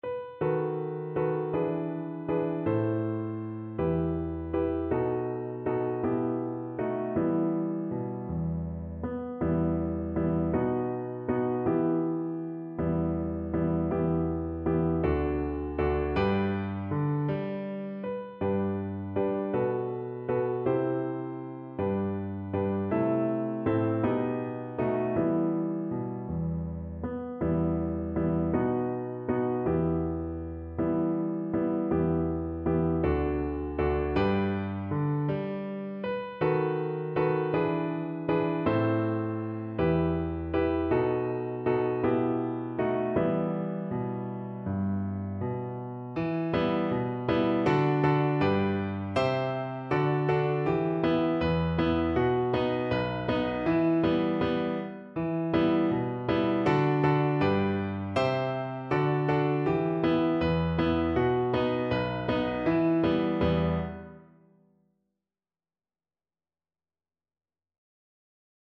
Cello
6/8 (View more 6/8 Music)
E minor (Sounding Pitch) (View more E minor Music for Cello )
Andante
Traditional (View more Traditional Cello Music)